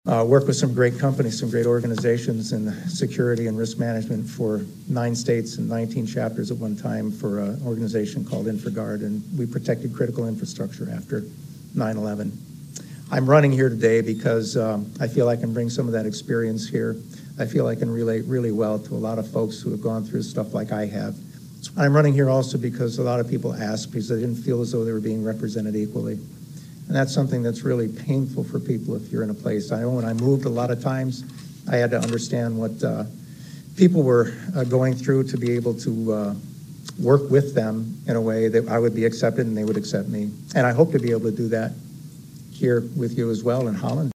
HOLLAND, MI (WHTC-AM/FM, Sept. 19, 2023) – The two men vying to be Mayor of Holland faced each other on Monday night.